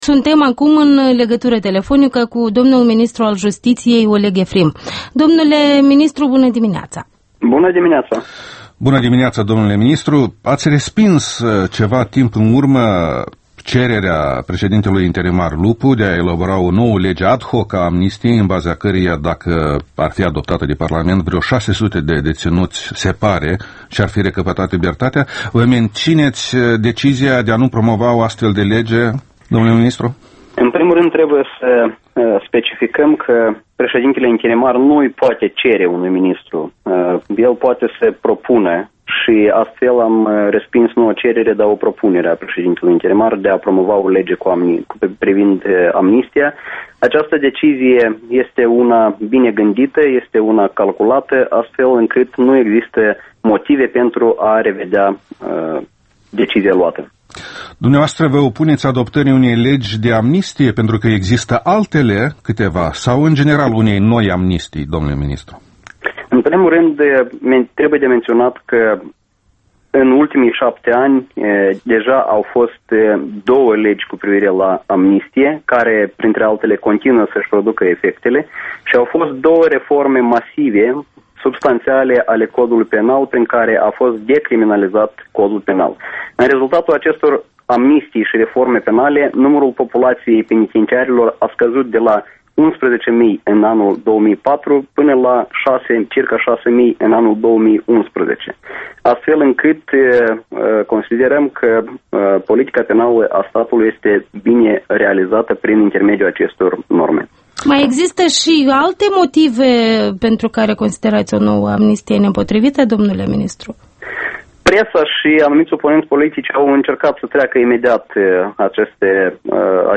Interviul matinal: cu Oleg Efrim, ministrul justiției, despre controversa în jurul legii amnistiei